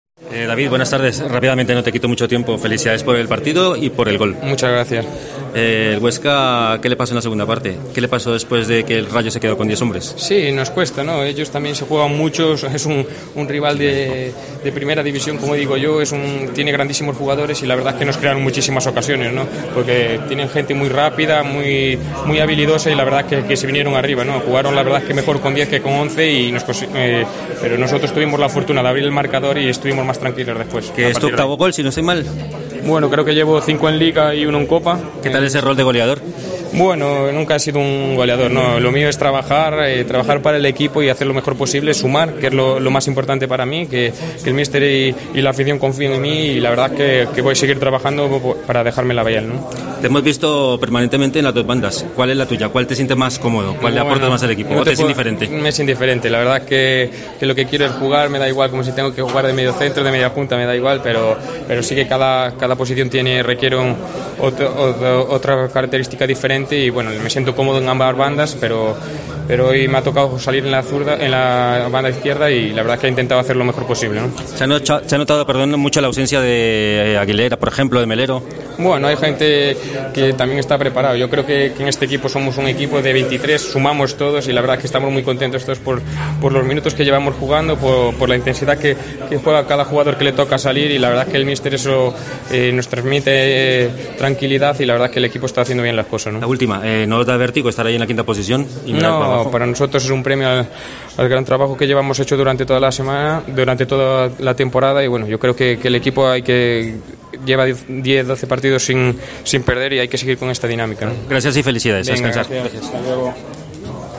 habla con David Ferreiro en zona mixta